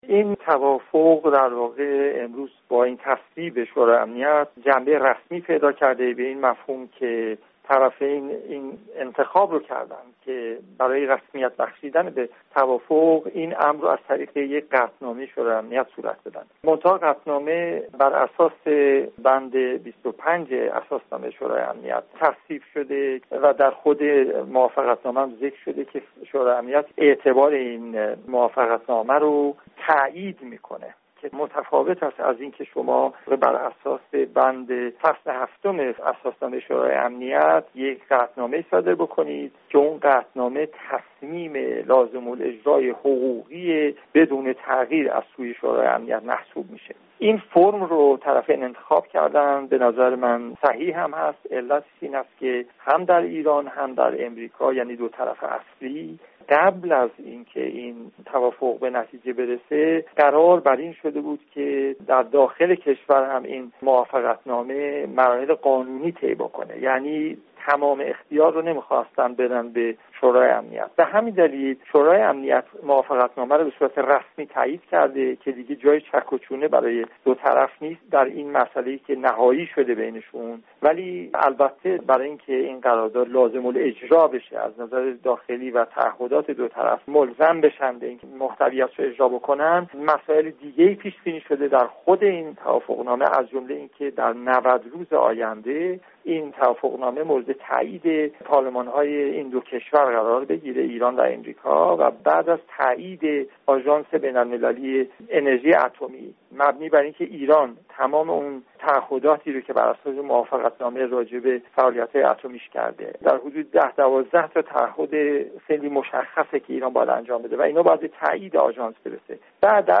گزارش‌های رادیویی